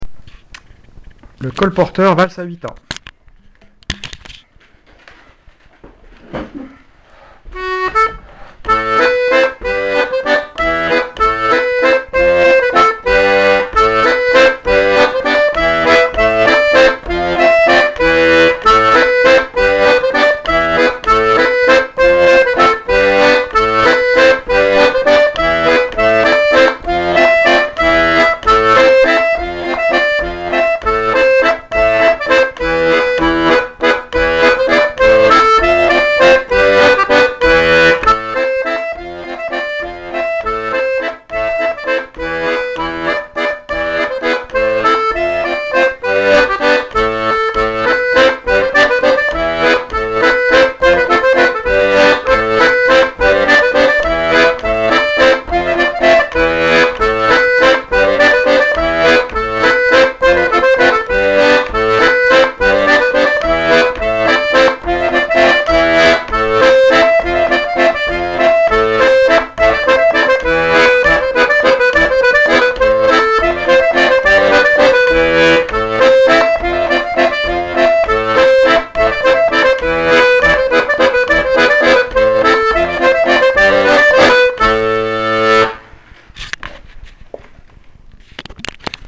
l'atelier d'accordéon diatonique
1) les rythmes à 8 temps (4X2, le colporteur);
Colporteur thème et des variations  (il y a beaucoup de notes, n'utiliser ces variations que de temps en temps...)